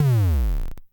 Index of /90_sSampleCDs/300 Drum Machines/Klone Dual-Percussion-Synthesiser/KLONE FILT NW8